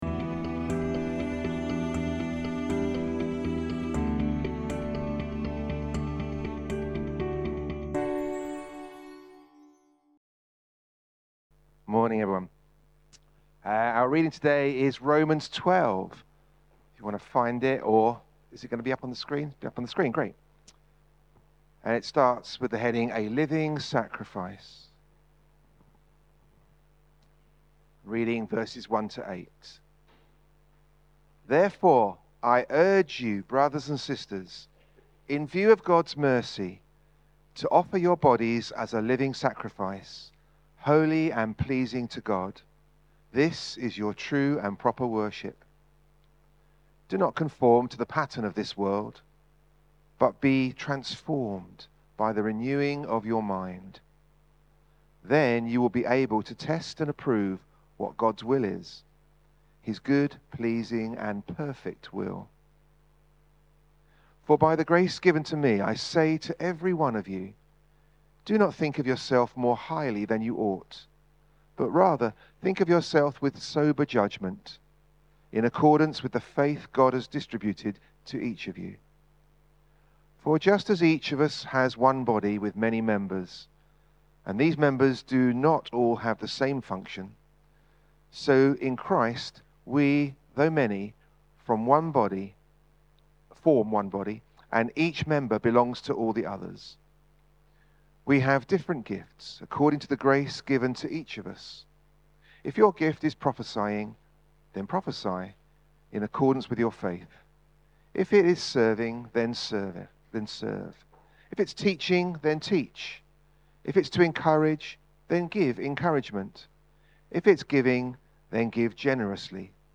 2025-10-05 Sunday Talk – Worship 1